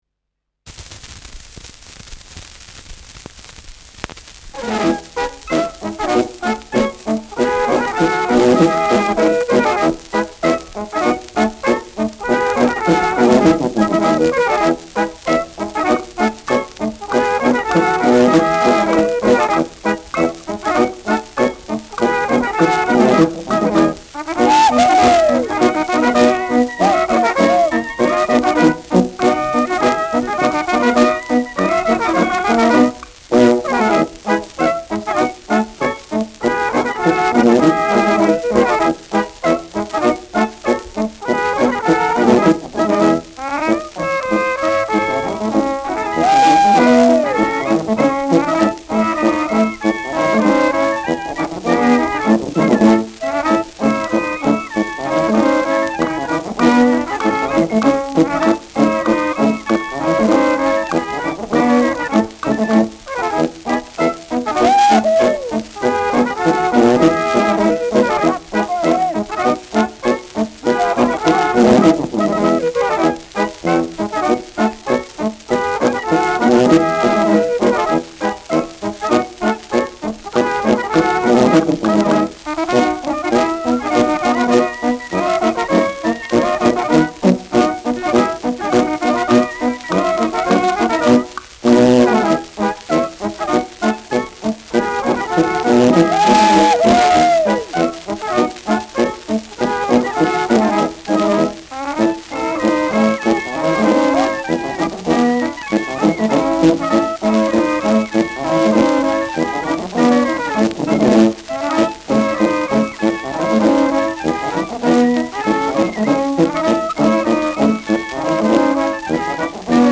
Schellackplatte
präsentes Rauschen : präsentes Knistern
Kapelle Pokorny, Bischofshofen (Interpretation)
Mit Klopfgeräuschen, Juchzern und Zwischenrufen.